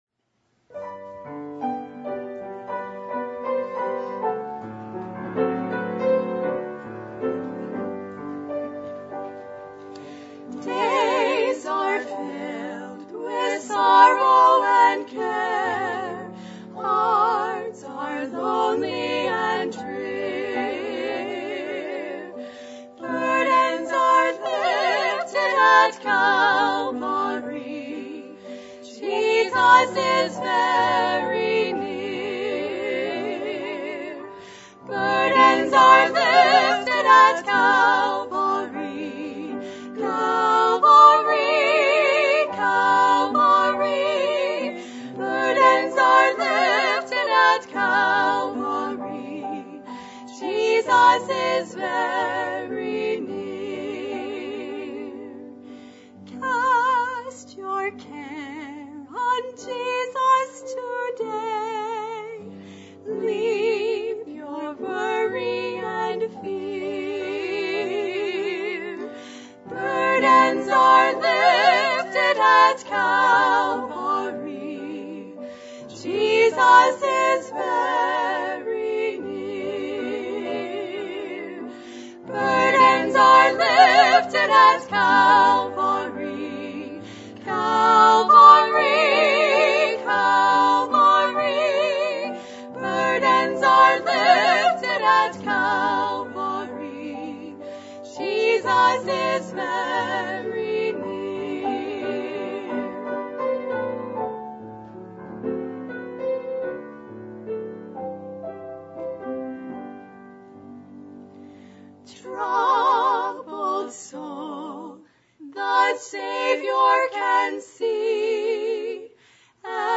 Joel 2:12-17 Service Type: Sunday Evening %todo_render% « The Prescription For Peace In Your Heart What Are We Living For?